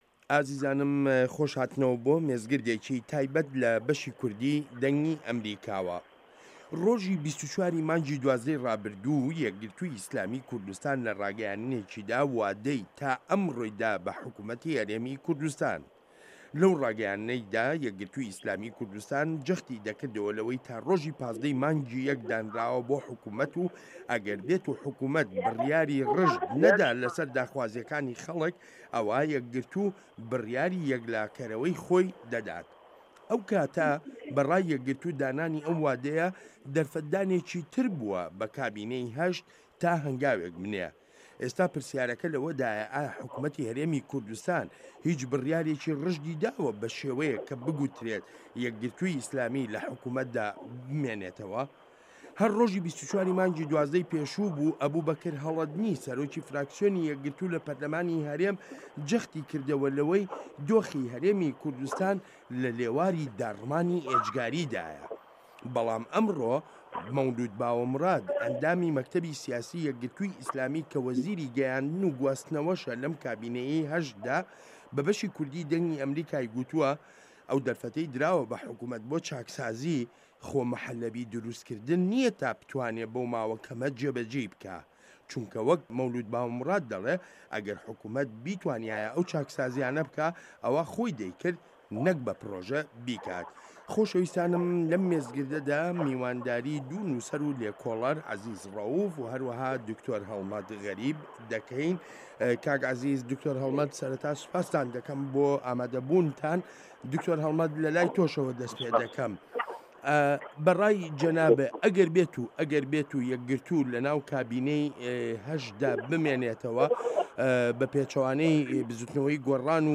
مێزگرد: یەکگرتووی ئیسلامی لە نێوان بەرداشی مانەوە یان کشانەوە لە حکومەت